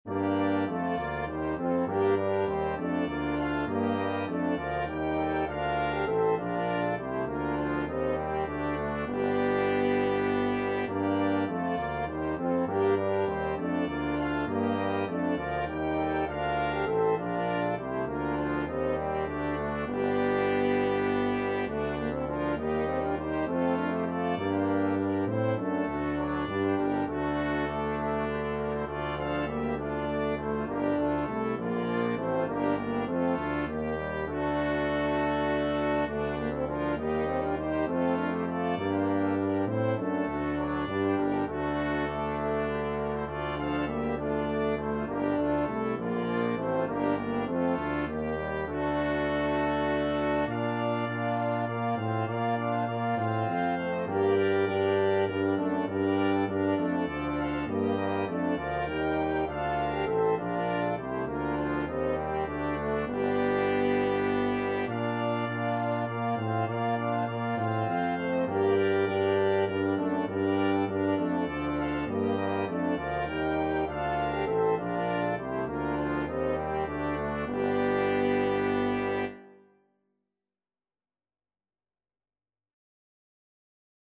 Free Sheet music for Brass Quintet
F major (Sounding Pitch) (View more F major Music for Brass Quintet )
3/2 (View more 3/2 Music)
Brass Quintet  (View more Intermediate Brass Quintet Music)
Classical (View more Classical Brass Quintet Music)